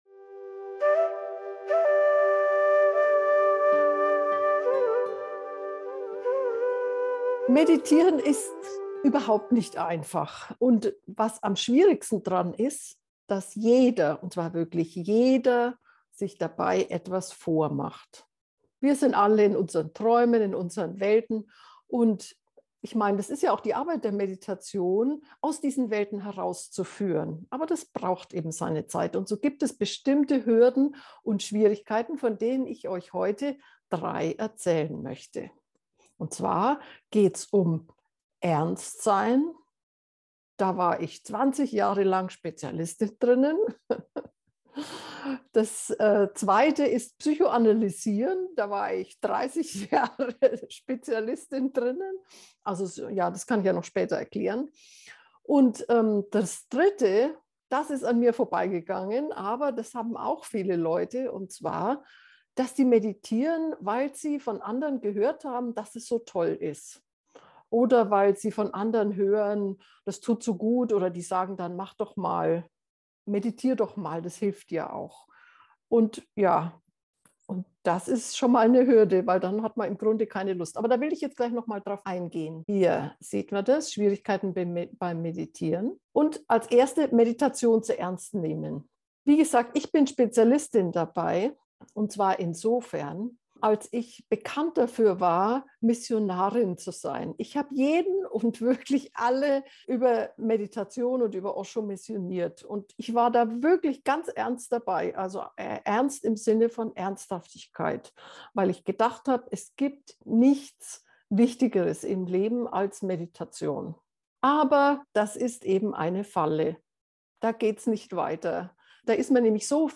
Hier beschreibe ich, wie sich diese 3 Hindernisse in meiner Meditationspraxis geäußert haben und was die Heilmittel sind mit denen sie hingenommen werden können. Danach machen wir noch eine Meditation, die dich zu deiner innersten Sehnsucht führt.
ernst-psychoanalysieren-nachahmen-gefuehrte-meditation.mp3